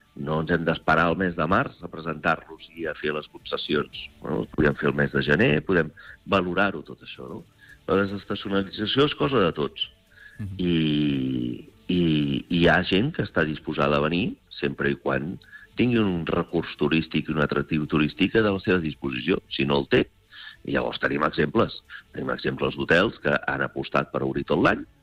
ha fet balanç del 2025 en una entrevista al Supermatí